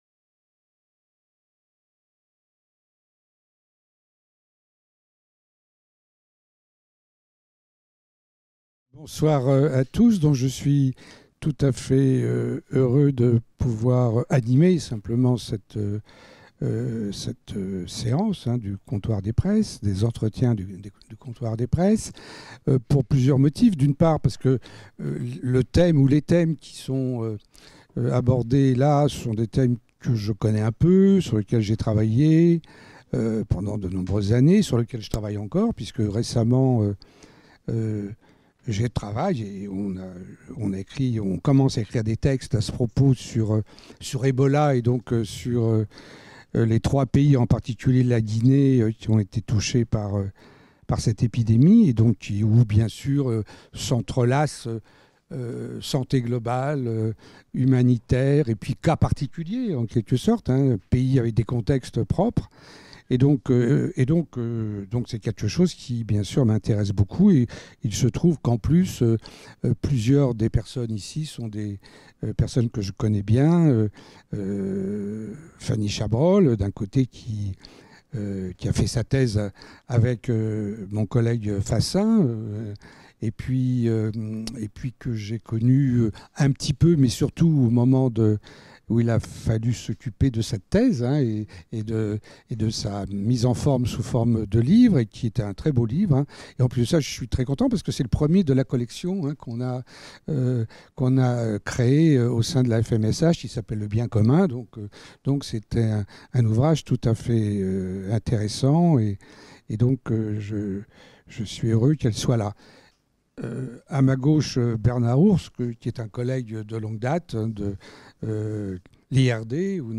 A quelles évolutions et réorientations, notamment dans le domaine de la santé y compris mentale, l'action humanitaire est-elle dorénavant confrontée ? L'entretien du 6 octobre réunit chercheurs et acteurs de l'aide humanitaire, auteurs de récentes publications universitaires qui répondront à ces questions.